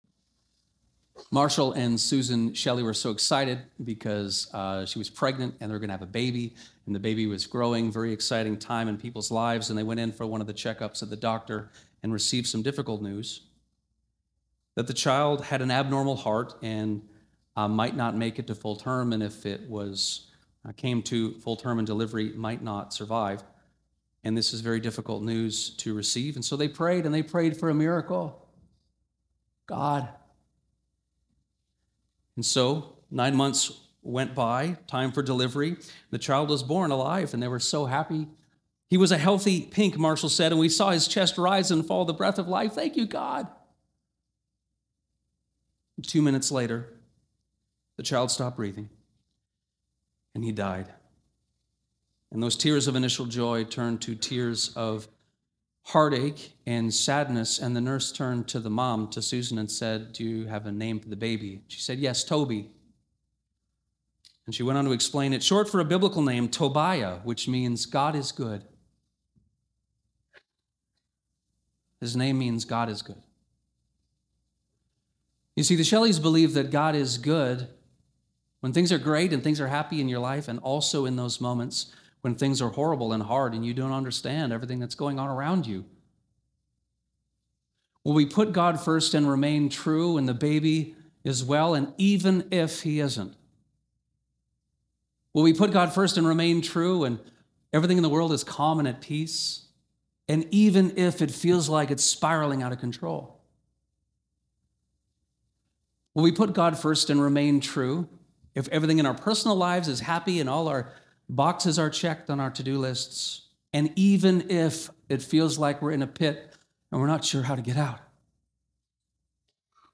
This sermon continues our series on the book of Daniel and explains chapter 3, one of its most famous stories. We use it to think about the sincerity of our own commitment to God, the priority we place on truly glorifying him, and the importance of “starting small.”